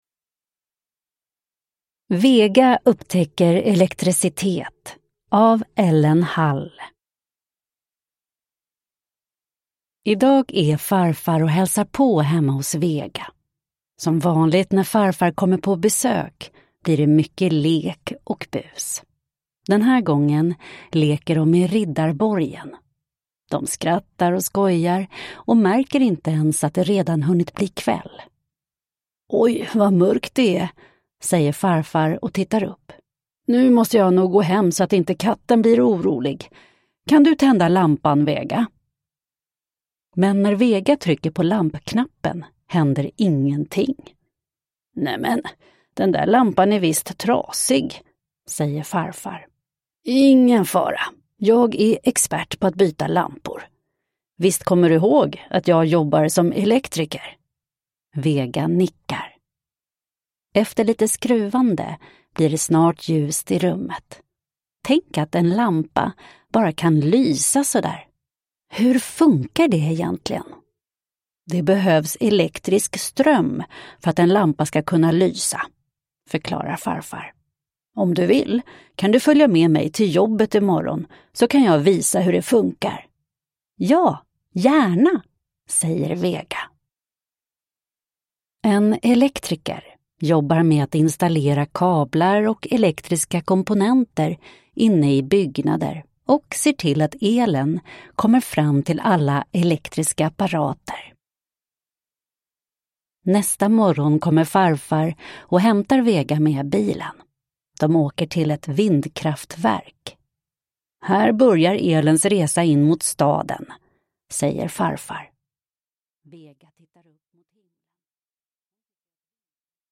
Vega upptäcker elektricitet (ljudbok) av Ellen Hall